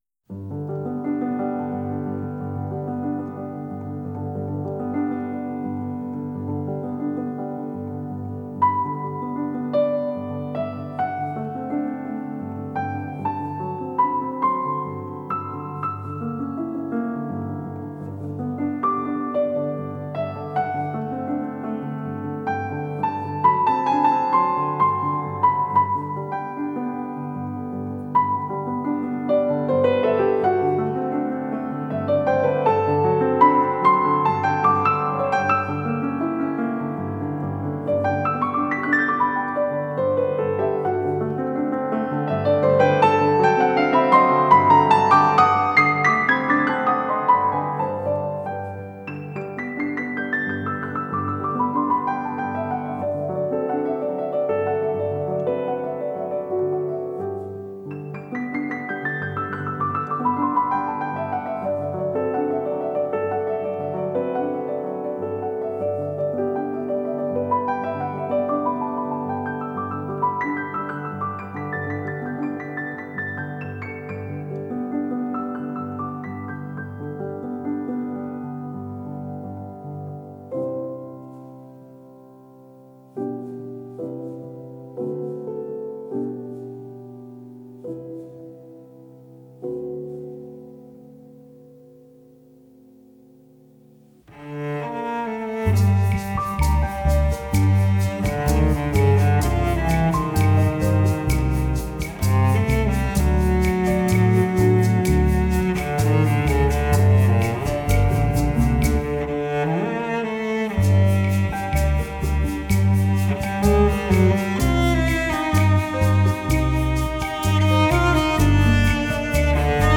Jazz,Latin
Recorded at Stiles Recording Studio in Portland, Oregon.